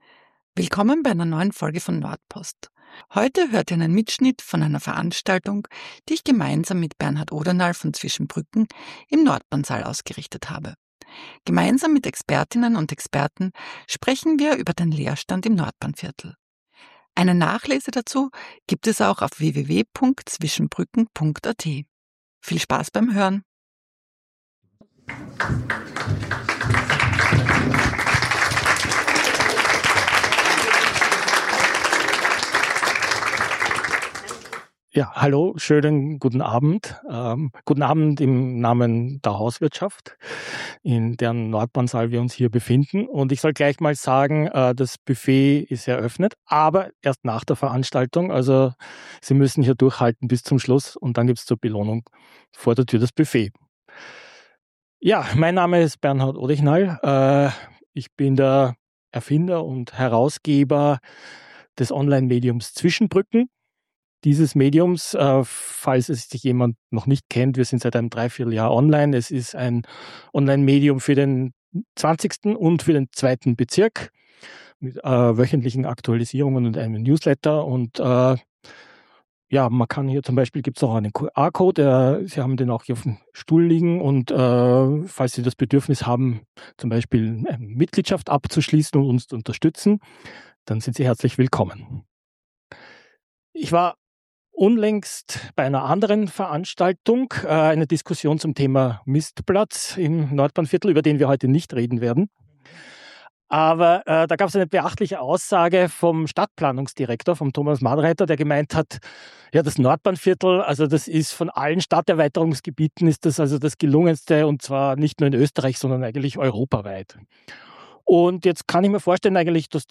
#63 - Lösungen für den Leerstand? (Podiumsdiskussion)